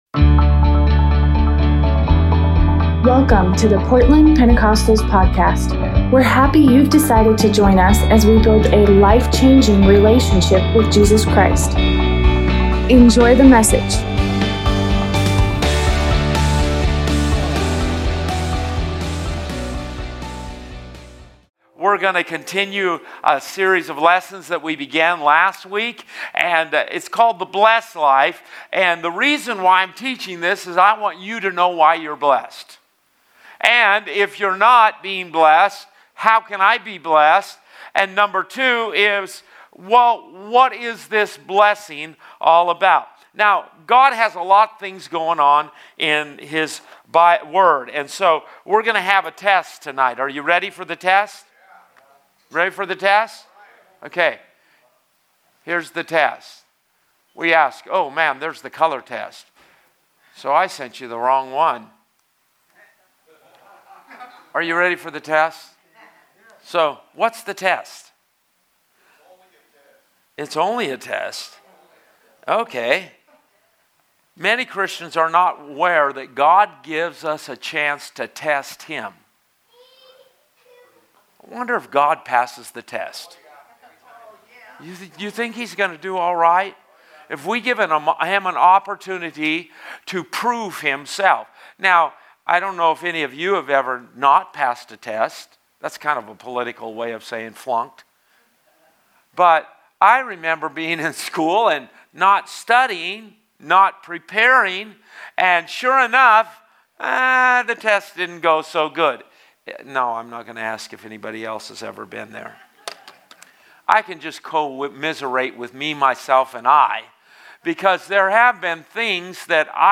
Lesson #2 of “The Blessed Life”.